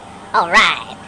Alright Sound Effect
alright.mp3